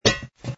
sfx_fstop_steel_male02.wav